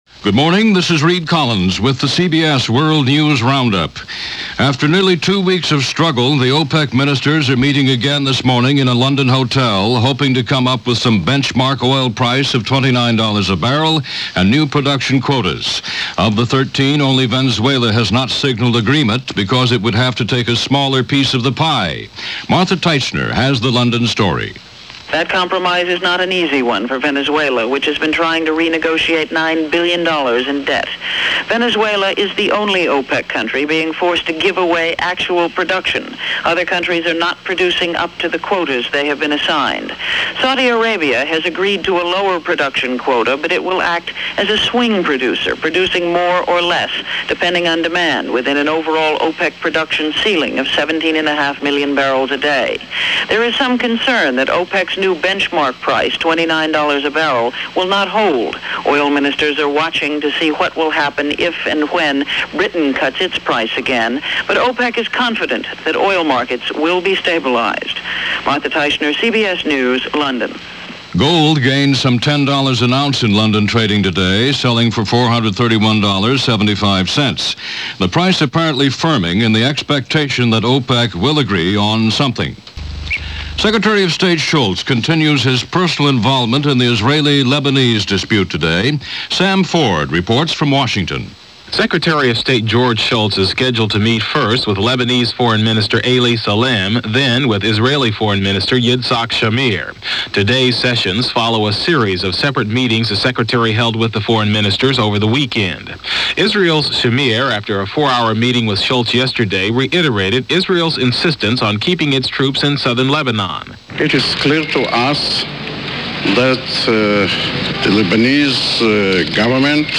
CBS World News Roundup – Hourly News